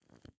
Minecraft Version Minecraft Version snapshot Latest Release | Latest Snapshot snapshot / assets / minecraft / sounds / block / candle / ambient4.ogg Compare With Compare With Latest Release | Latest Snapshot